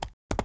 horse_run.wav